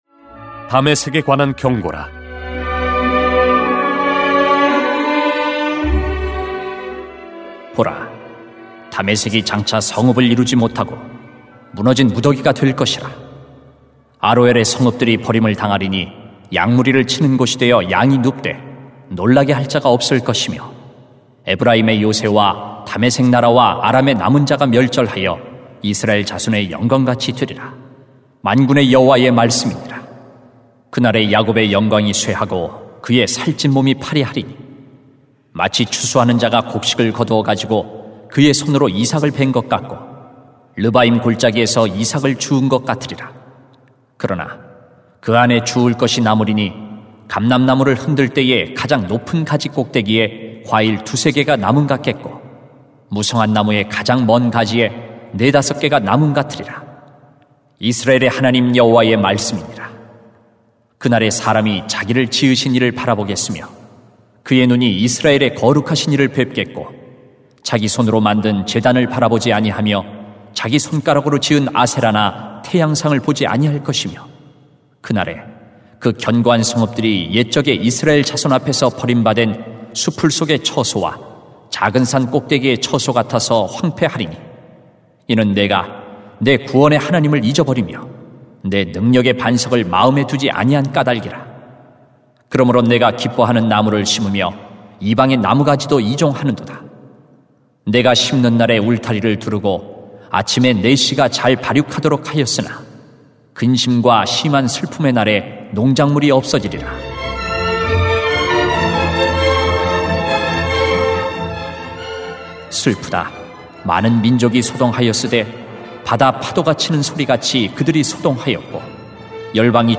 2020.8.2 크신 하나님을 경외해야 합니다 > 주일 예배 | 전주제자교회